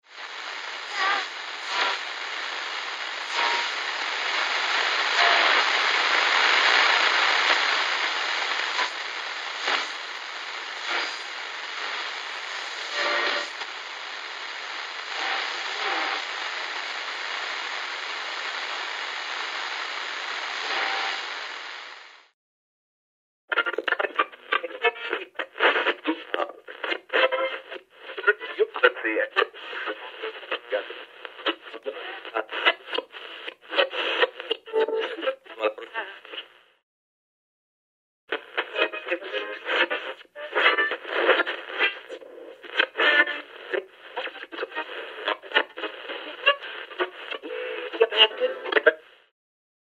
Звук настройки радиоволн при поиске радиостанций